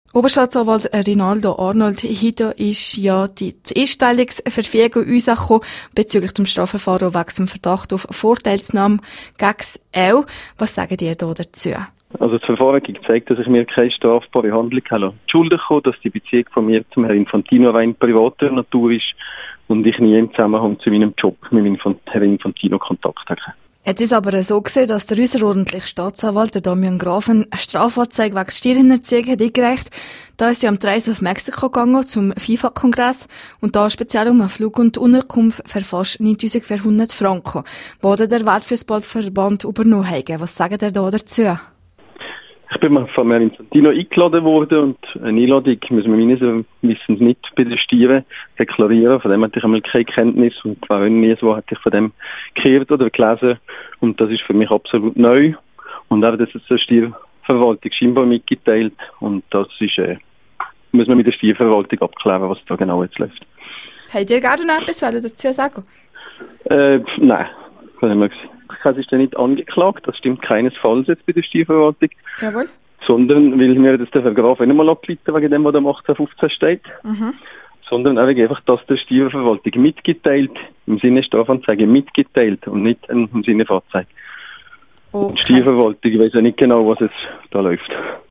Es gilt die Unschuldsvermutung./ip Interview mit Oberstaatsanwalt Rinaldo Arnold wegen des Verdachts der mutmasslichen Steuerhinterzieh (Quelle: rro)